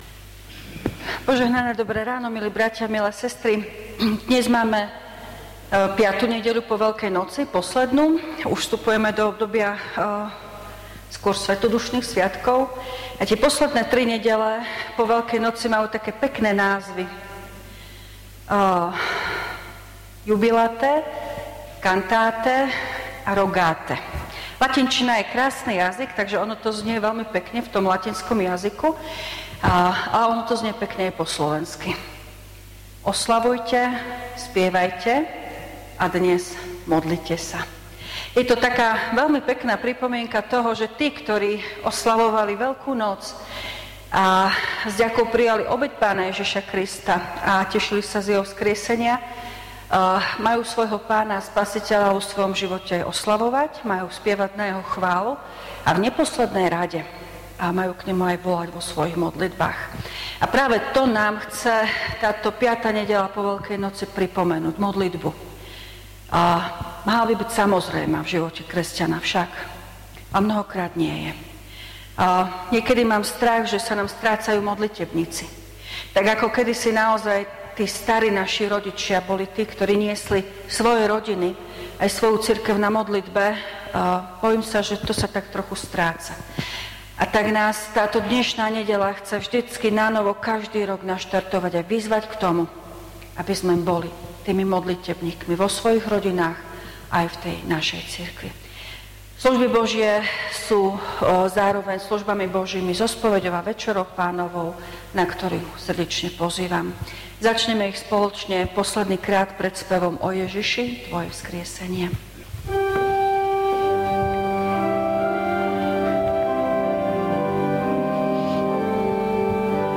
V nasledovnom článku si môžete vypočuť zvukový záznam zo služieb Božích – 5. nedeľa po Veľkej noci.